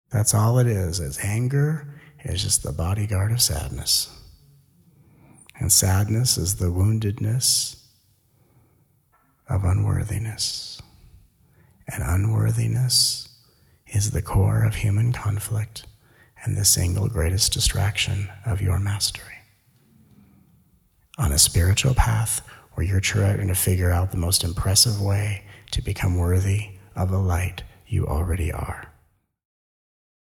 Join us for this incredible download recorded during the 2017 Denver and Boulder Colorado Tour. 13 tracks; total time 7 hrs, 58 mins.